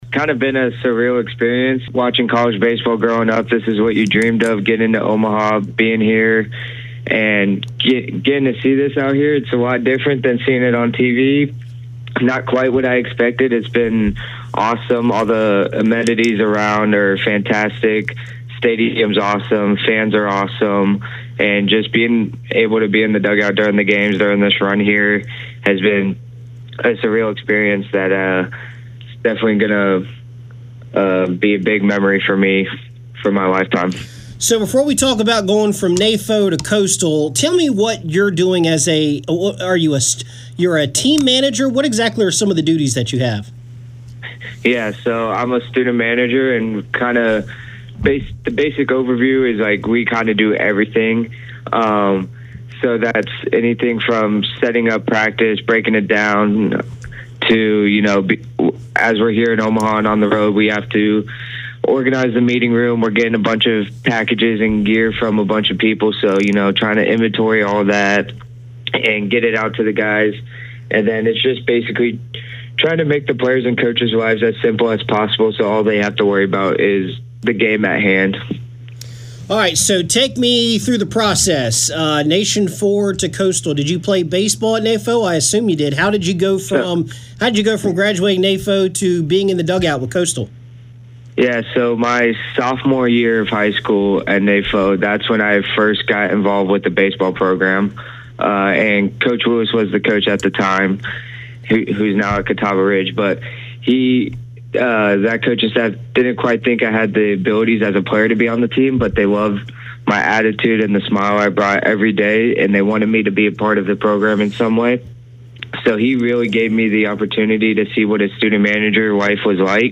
The Chants will be playing for a National Championship this weekend. This is the full interview of which has been cut and edited for news to play the next two days on WRHI and Interstate 107.